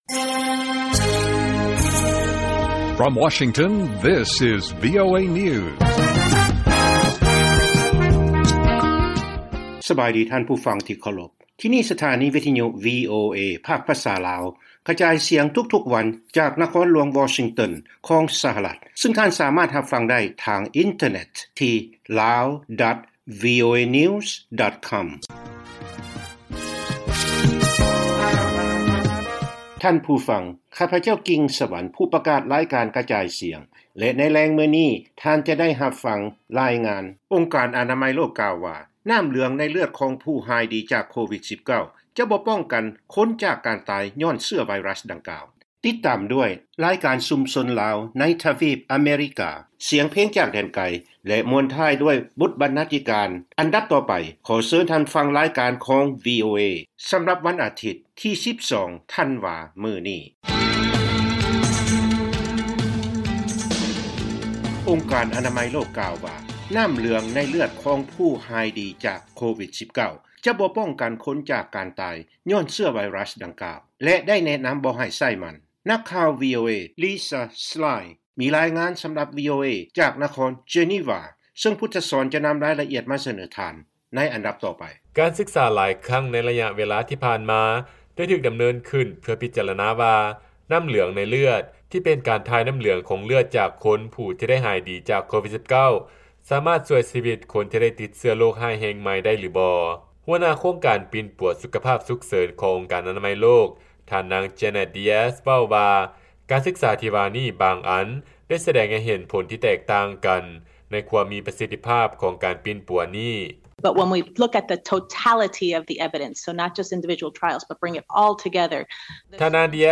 ລາຍການກະຈາຍສຽງຂອງວີໂອເອ ລາວ
ວີໂອເອພາກພາສາລາວ ກະຈາຍສຽງທຸກໆວັນ ເປັນເວລາ 30 ນາທີ. ພວກເຮົາສະເໜີລາຍງານກ່ຽວກັບອົງການອະນາໄມໂລກ ແນະນຳບໍ່ໃຫ້ໃຊ້ ນໍ້າເຫຼືອງໃນເລືອດ ເພື່ອປິ່ນປົວຄົນໄຂ້ COVID-19, ຊຸມຊົນຊາວລາວໃນທະວີບອາເມຣິກາ ລາຍການສຽງເພງຈາກແດນໄກ ແລະບົດບັນນາທິການ.